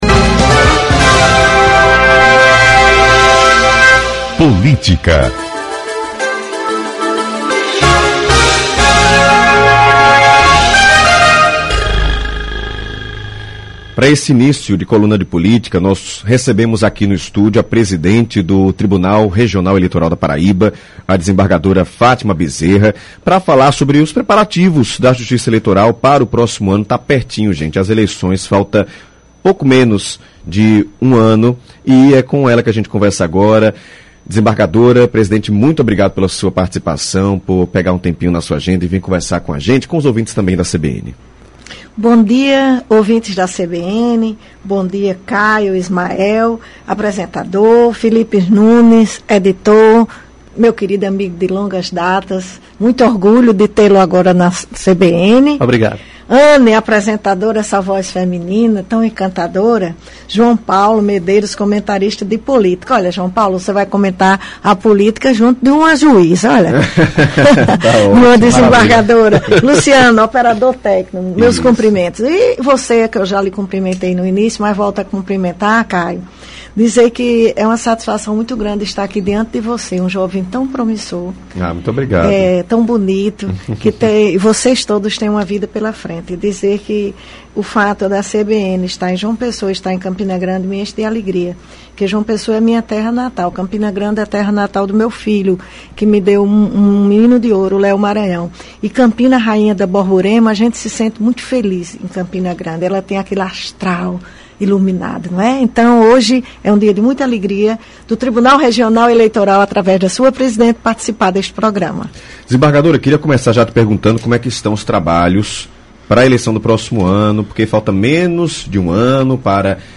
Entrevista: Fátima Bezerra, presidente do TRE-PB – CBN Paraíba
Faltando um ano para as eleições municipais de 2024, a CBN Paraíba inicia a contagem regressiva com informações sobre o planejamento para o pleito. Nesta segunda (9), recebemos no estúdio a presidente do Tribunal Regional Eleitoral da Paraíba (TRE-PB), a desembargadora Fátima Bezerra.